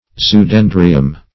Search Result for " zoodendrium" : The Collaborative International Dictionary of English v.0.48: Zoodendrium \Zo`o*den"dri*um\, n.; pl.